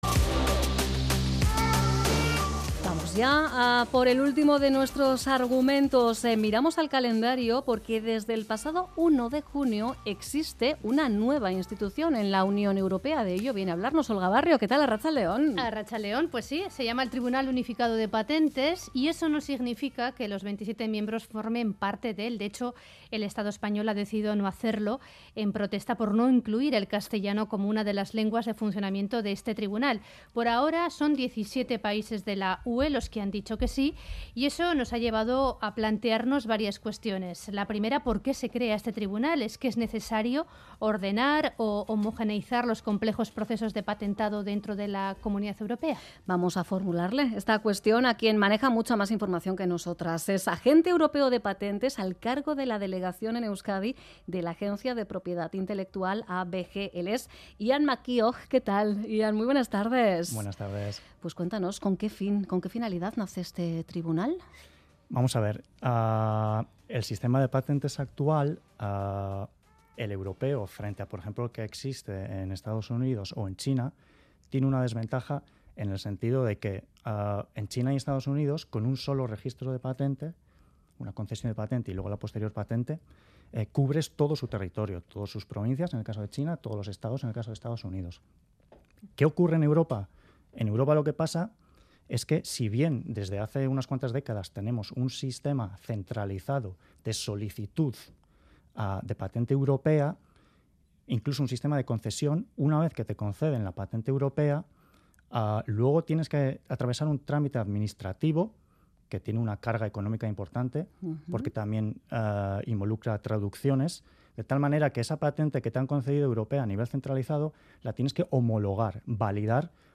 Puedes también descargar el audio de la entrevista pinchando aquí.